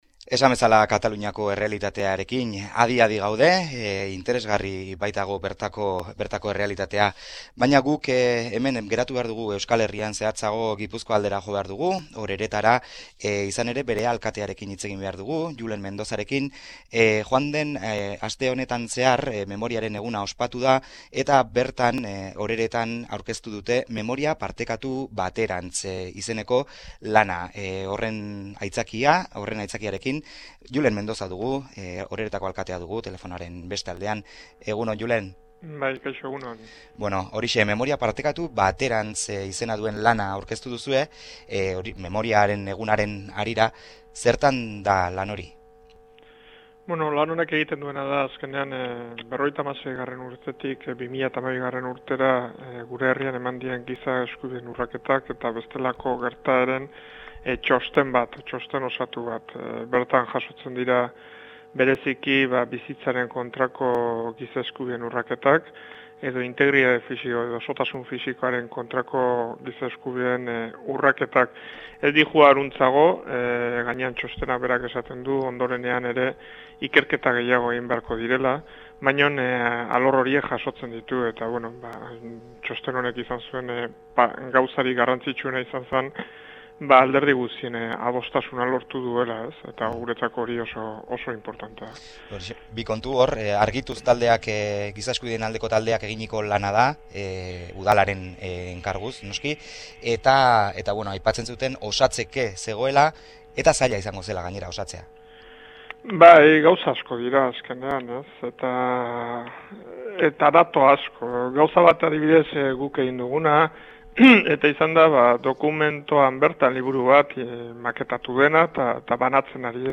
HIZPIDEA: Julen Mendoza Errenteriako alkatearekin oroimenaz hizketan